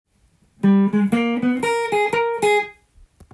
G7を例にフレーズを作ってみました。
③は７ｔｈコードで使用される代表的スケール
オルタードスケールをもろに弾いているフレーズです。
かなり渋いですね。